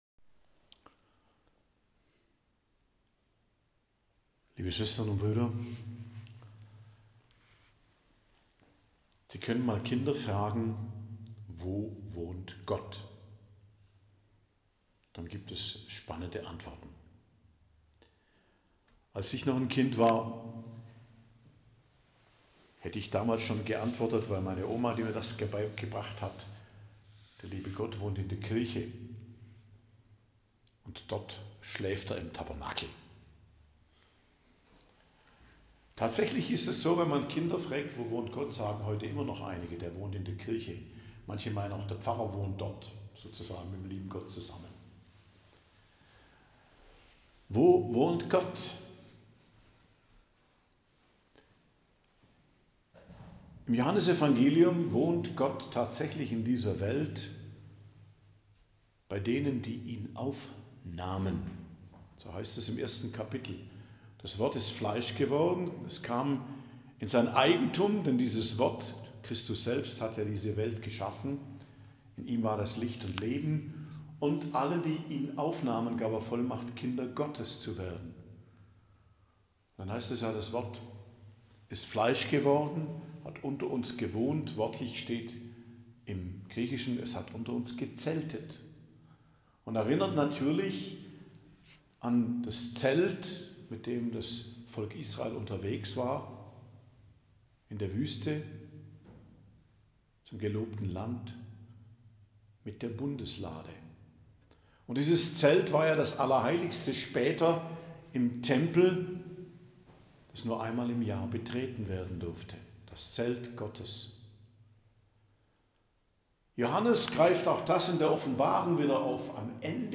Predigt am Donnerstag der 2. Woche i.J. 23.01.2025